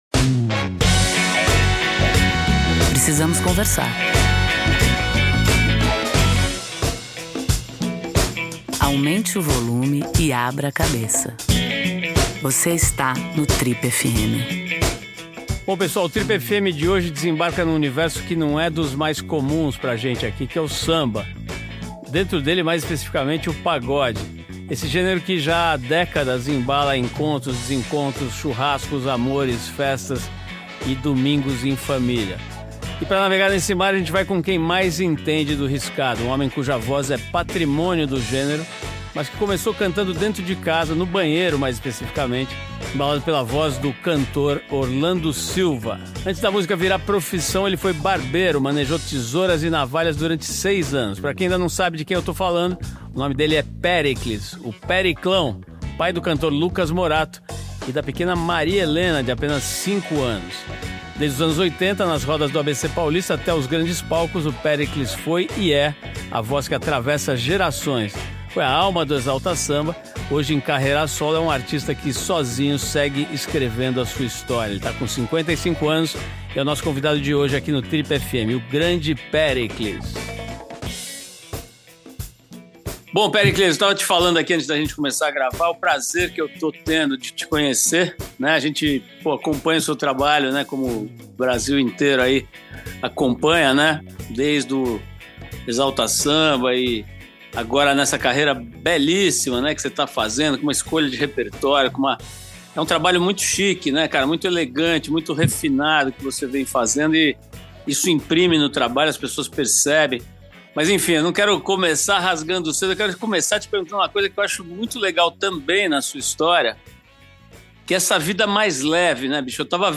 Na entrevista para o Trip FM, entre outras coisas, Caio contou sobre os bastidores da gravação dessa cena e refletiu sobre suas experiências morando no Capão Redondo, na periferia de São Paulo, e na favela do Vidigal, no Rio de Janeiro.